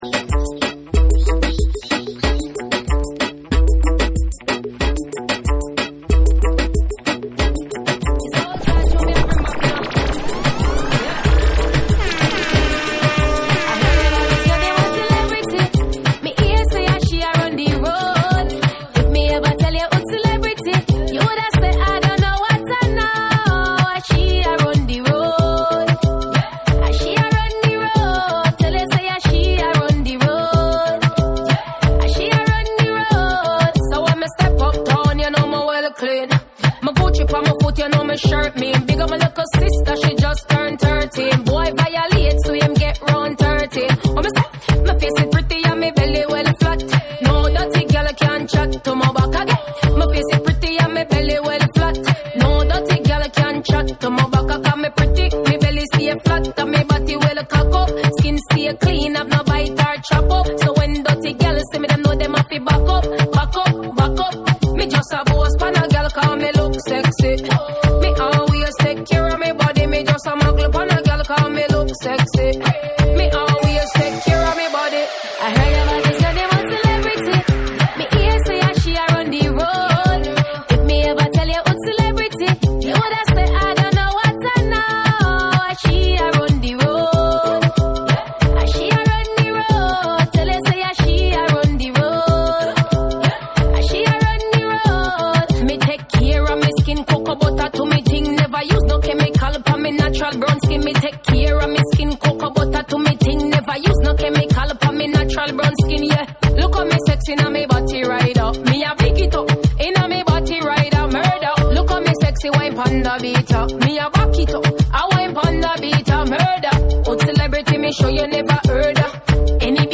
JAPANESE / REGGAE & DUB / NEW RELEASE(新譜)